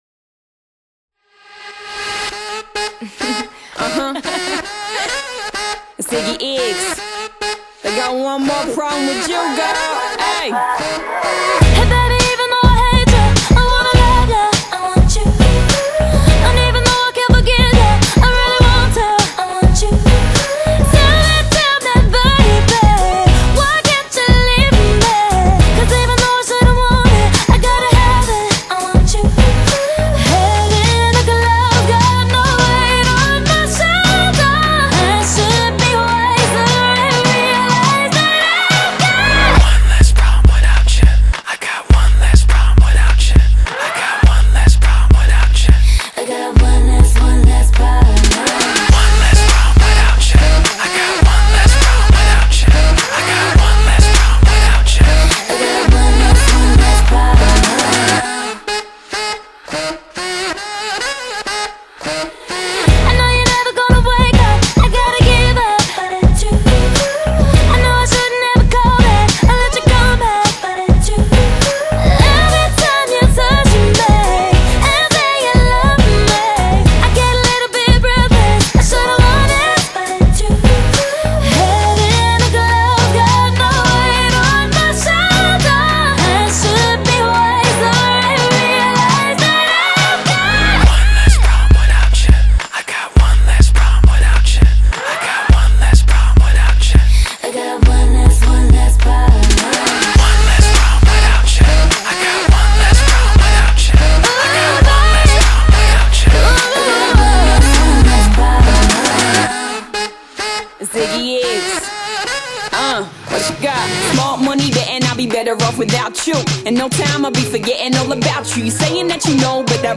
Genre: Hip Hop/R&B